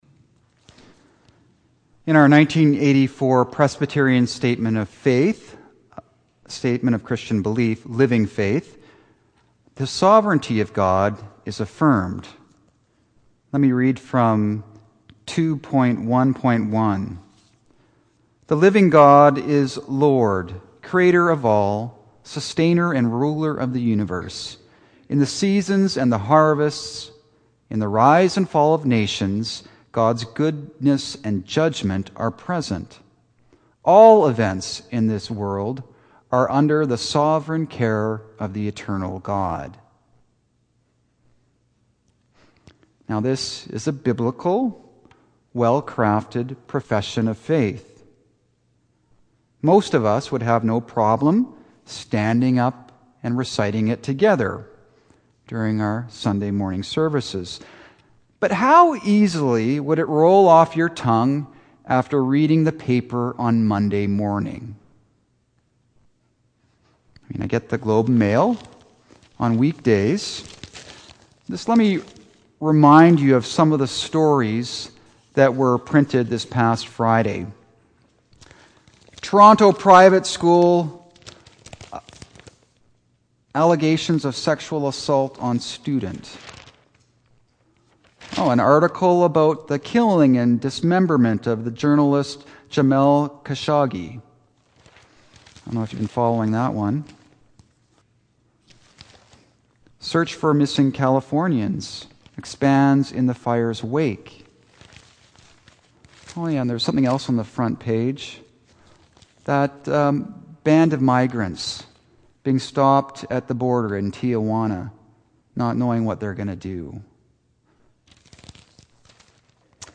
Services from St. Andrew's | St. Andrew’s Presbyterian Church